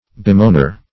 Bemoaner \Be*moan"er\, n. One who bemoans.